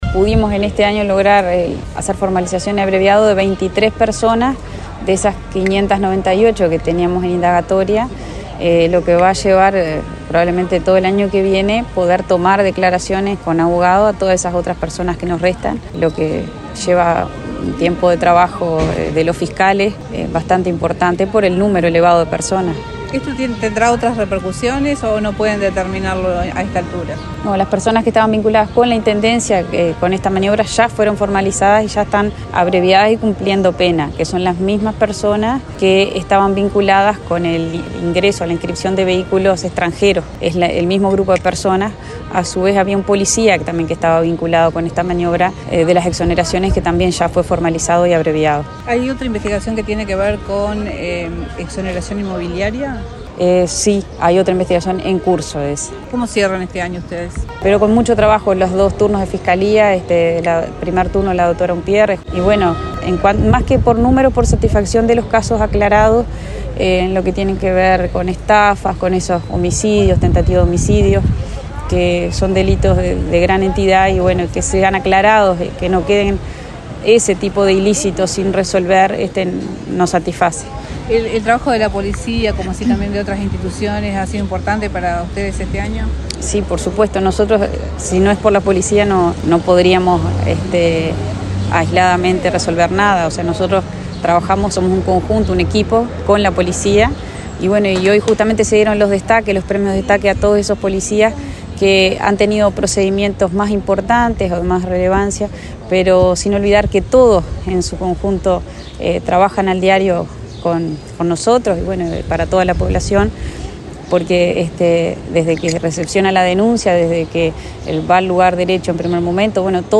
Escuchar la entrevista a la fiscal Letizia Siqueira: